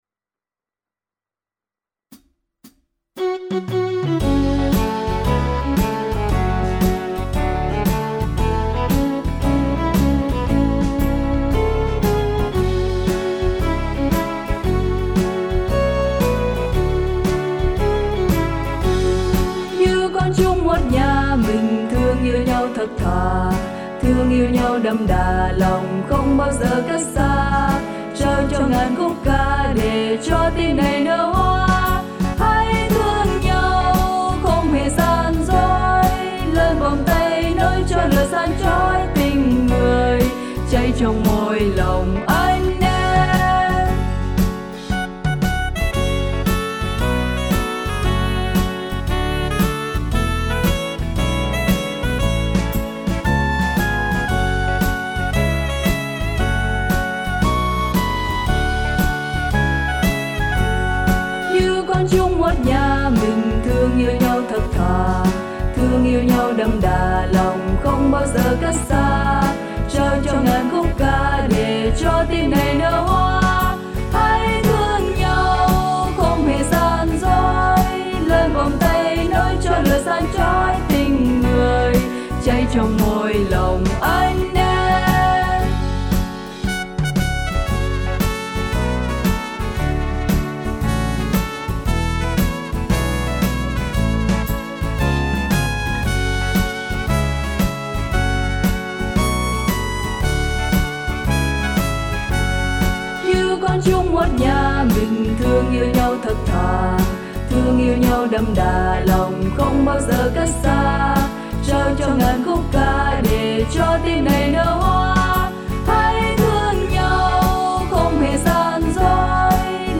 Tone Nam (Am) / Nữ (F#m)